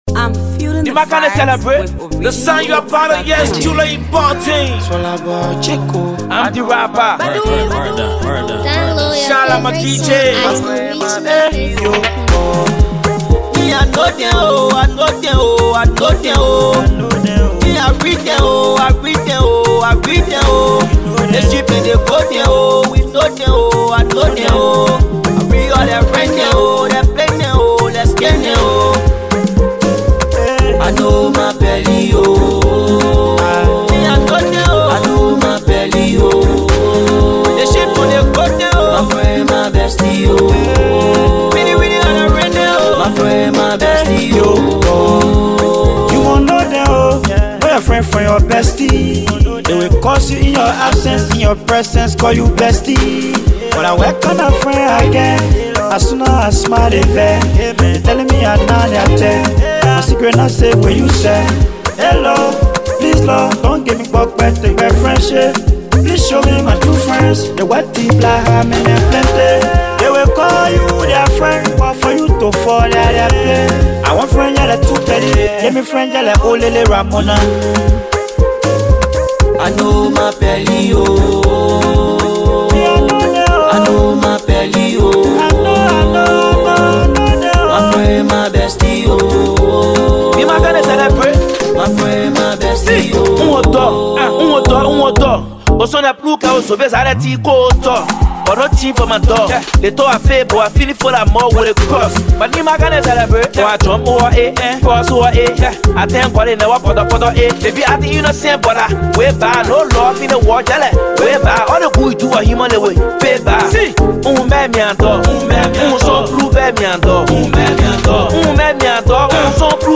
studio effort
This energetic track